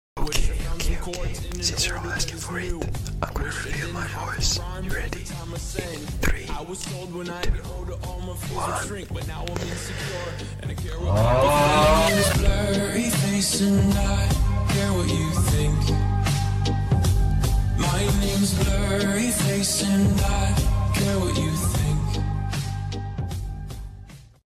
En Annorlunda Edit, ASMR Edit Sound Effects Free Download